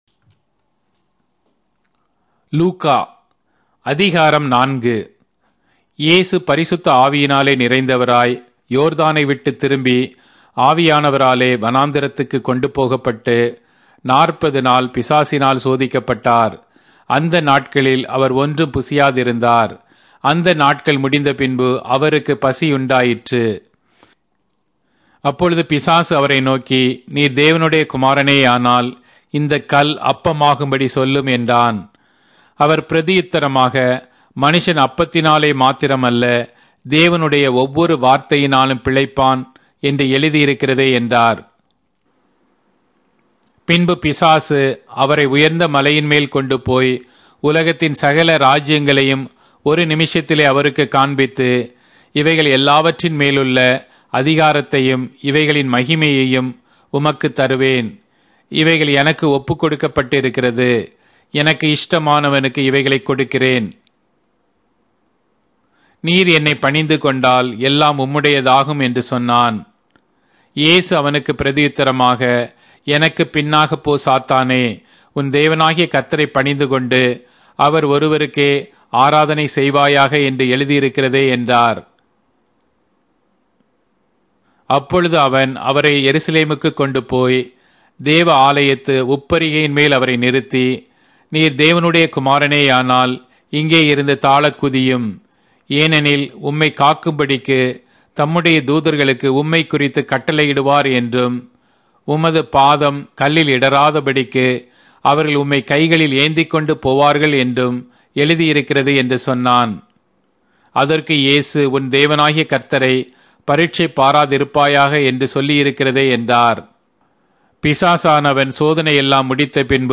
Tamil Audio Bible - Luke 17 in Akjv bible version